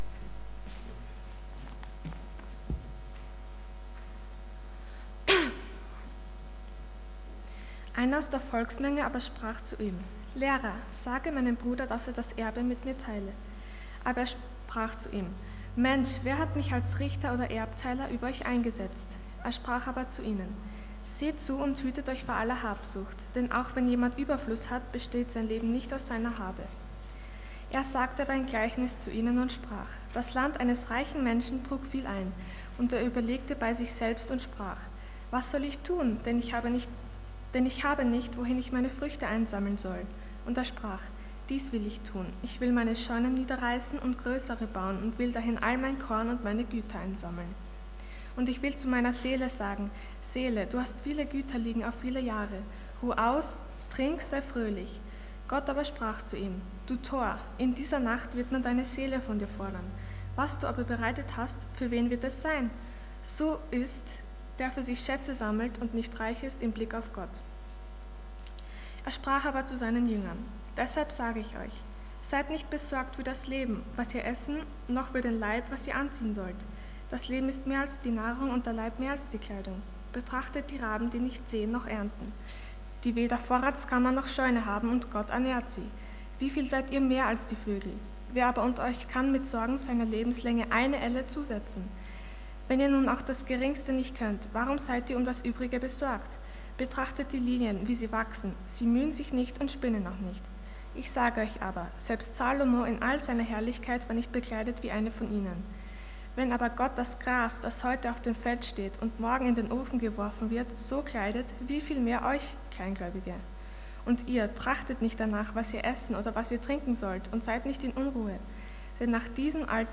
Dienstart: Sonntag Morgen